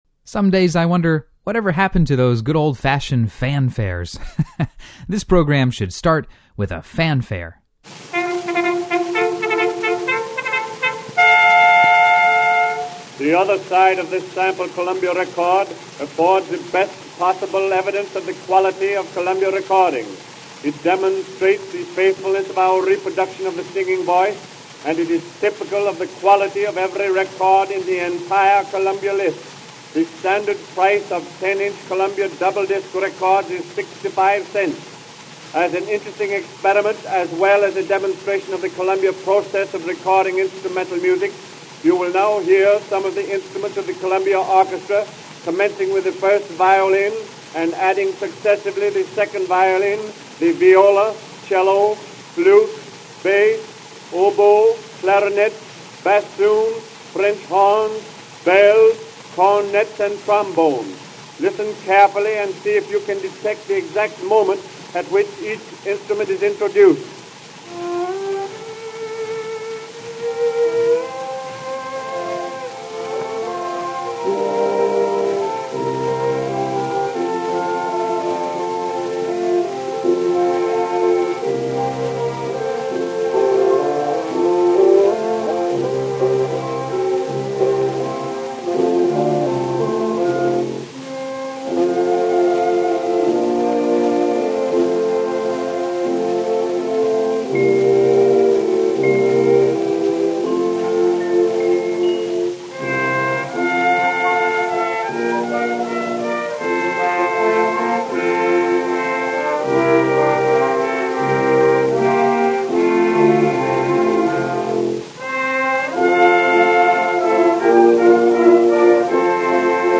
a country classic
hot fiddle
celeste solo
That calls for a fancy fanfare!